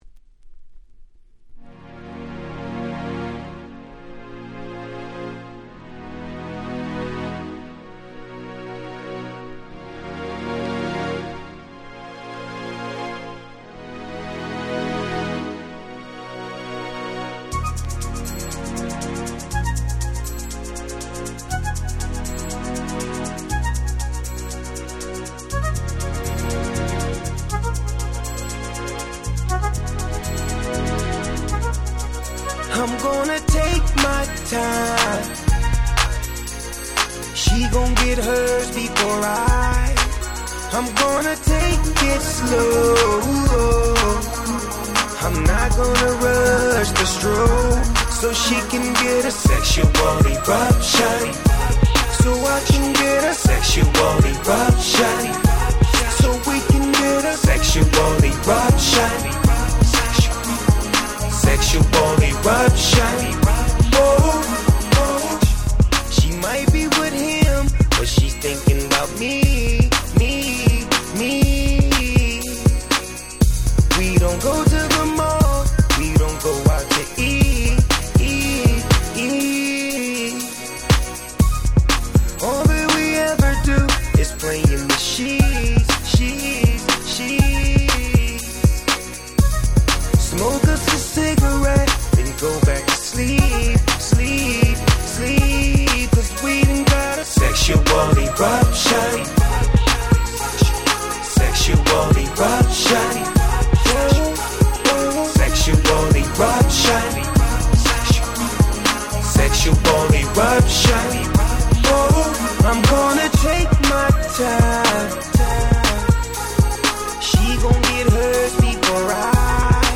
07' Super Hit Hip Hop !!